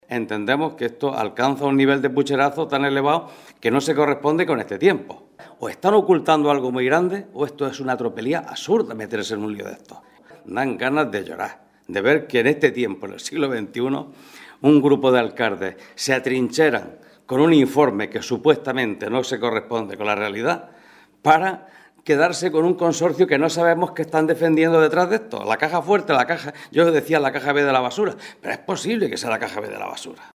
Rueda de prensa que ha ofrecido el PSOE de Almería sobre el Consorcio de Levante